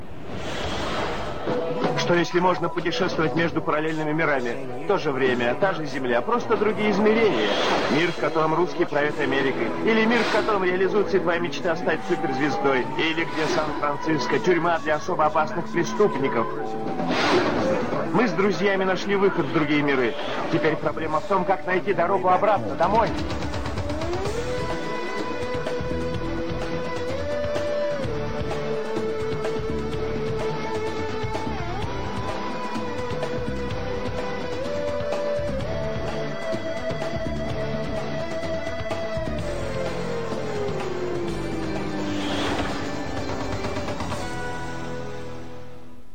Im Intro wird während der US-Titeleinblendung von Sliders dieser russischer Name von einem Sprecher genannt.
Ganz leise ist dabei auch die originale Version aus den USA zu hören.